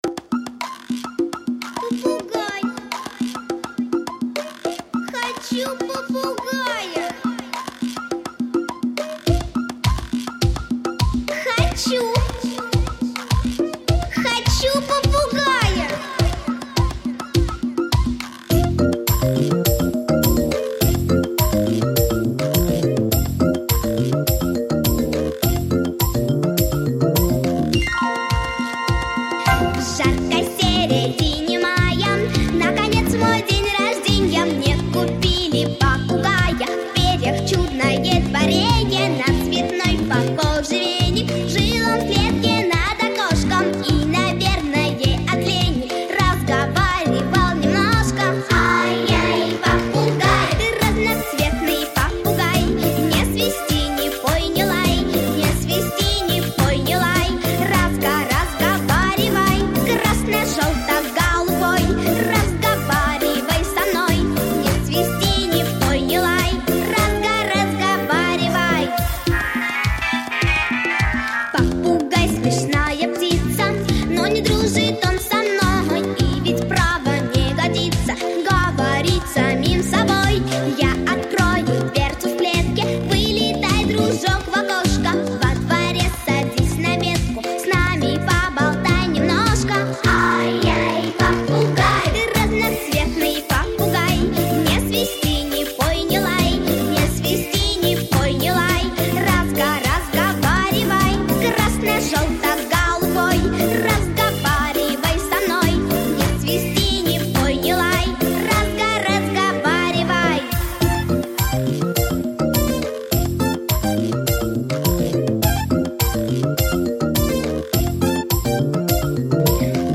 🎶 Детские песни / День рождения 🎂